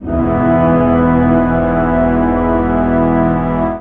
55aa-orc05-c#2.wav